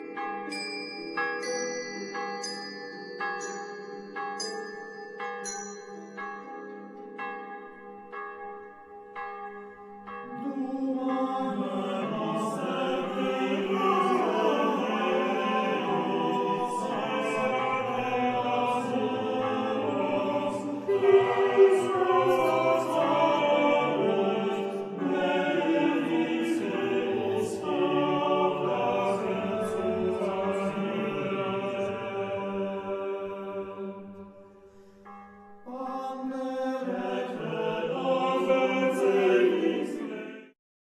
Współczesne prawykonania średniowiecznych utworów z Wrocławia, Cieszyna, Środy Śląskiej, Głogowa, Brzegu, Henrykowa, Żagania, kompozytorów anonimowych, Nicolausa Menczelliniego, hymny i sekwencja o św.
kontratenor, lutnia
fidel